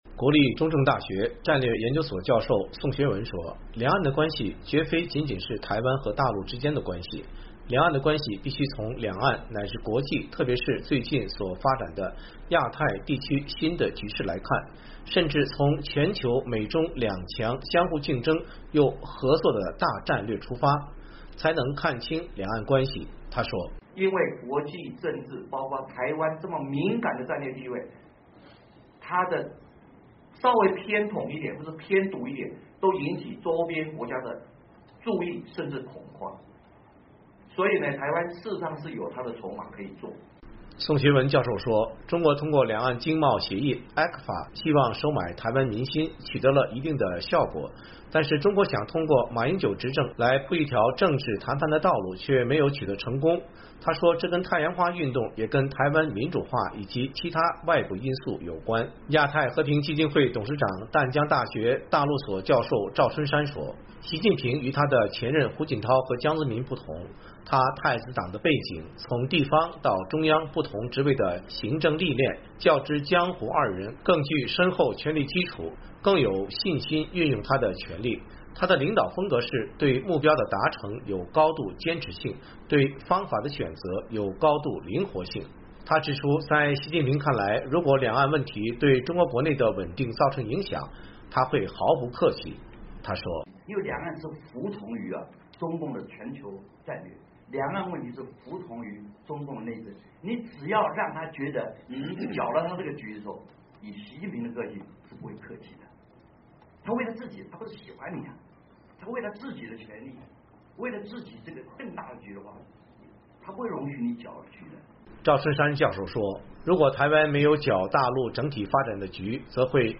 这些学者是在美国约翰霍普金斯大学高等国际研究院中国研究系主任蓝普顿教授的中文译本《从邓小平到习近平》新书发布及座谈会上发表以上评论的。